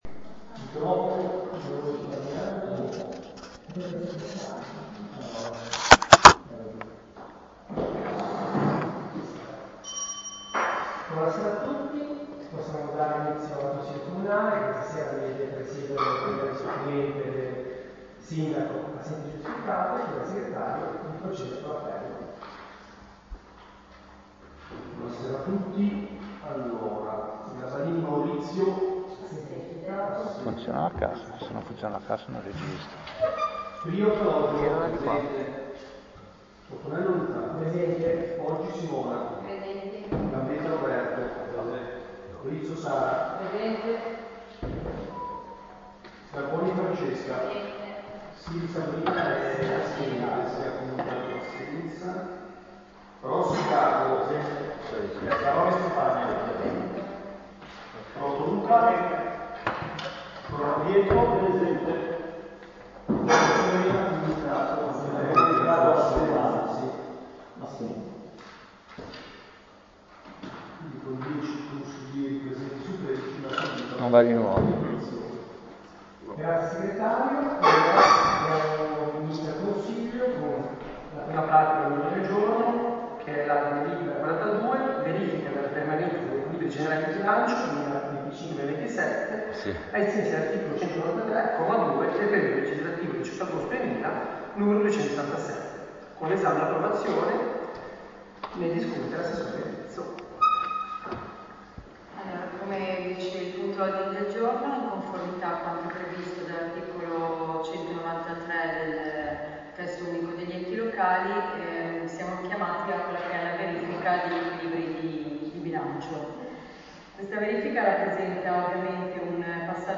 Consiglio Comunale del 30 luglio 2025 - Comune di Albisola Superiore
Seduta del Consiglio comunale mercoledì 30 luglio 2025, alle 21.00, presso l'Auditorium comunale in via alla Massa.